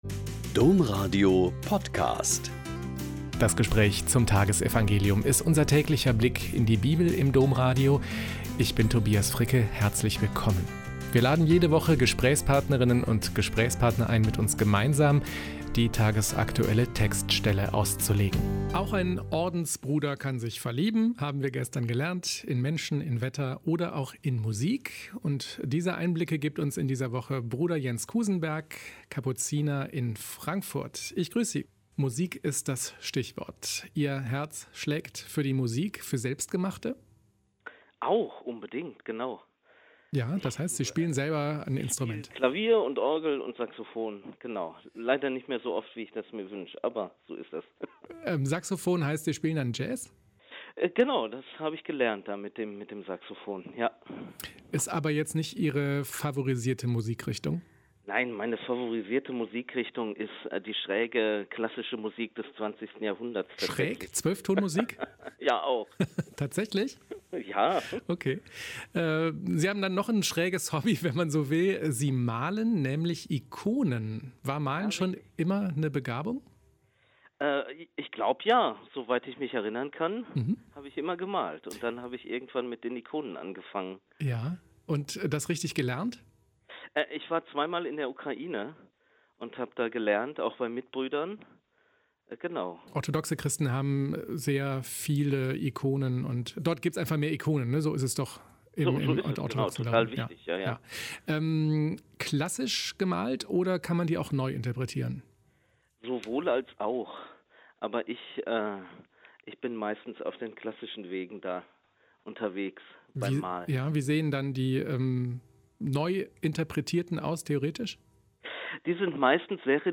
Lk 11,14-26 - Gespräch